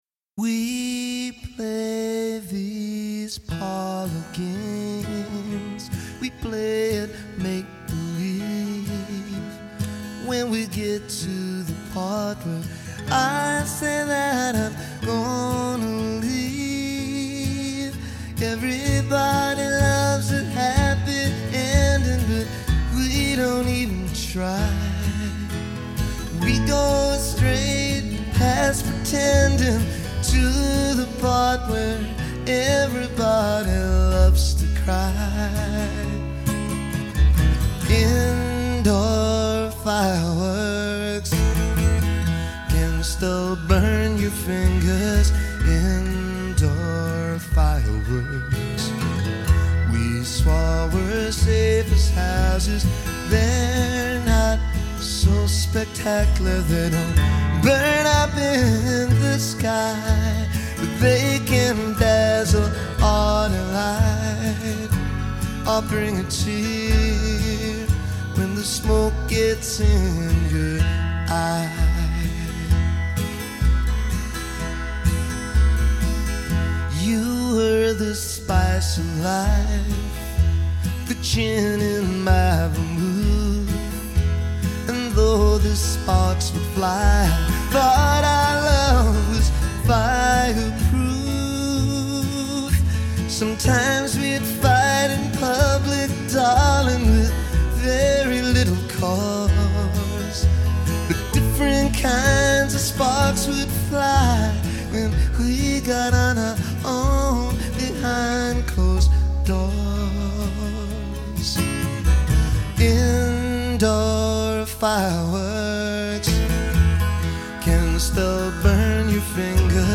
zeroing in on one of its signature sad ballads.